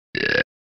Burp.mp3